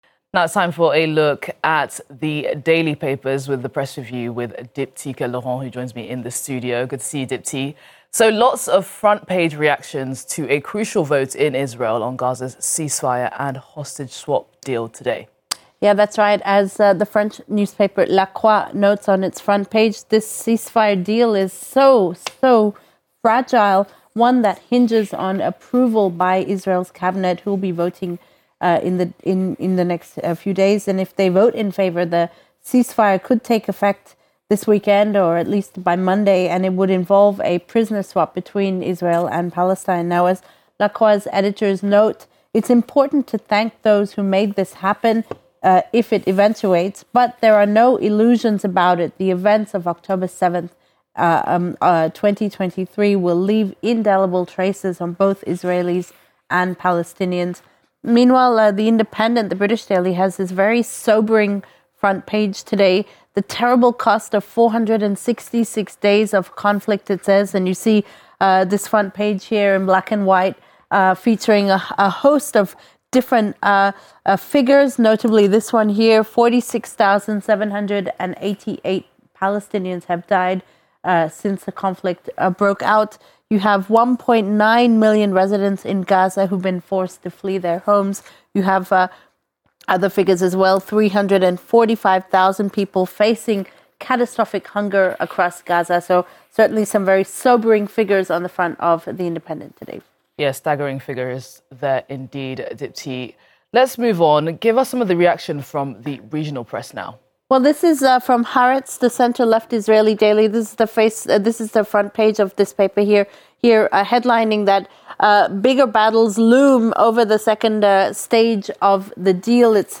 An overview of the stories making the French and international newspaper headlines. From Monday to Friday live at 7:20am and 9:20am Paris time.